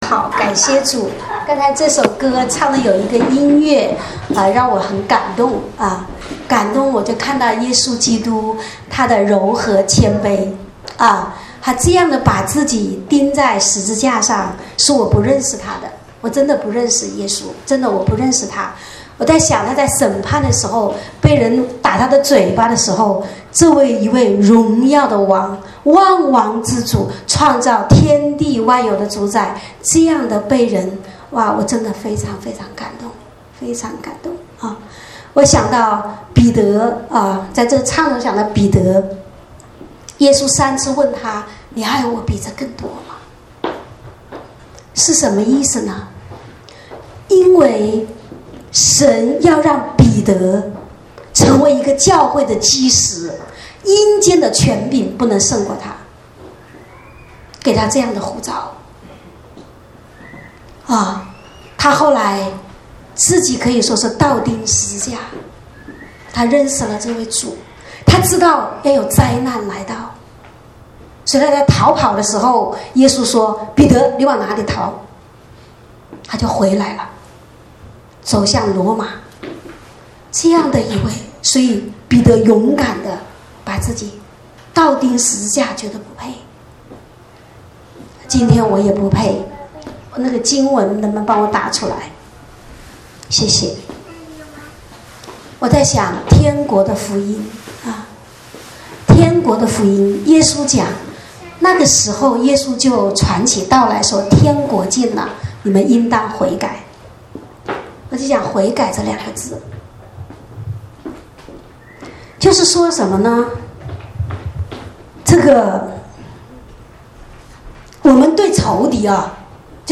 主日恩膏聚会